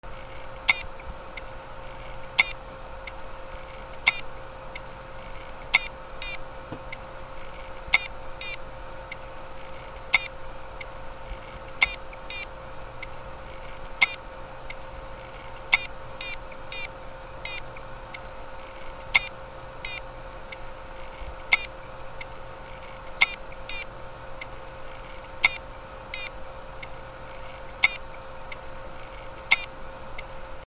ハードディスクからの異音
/ M｜他分類 / L10 ｜電化製品・機械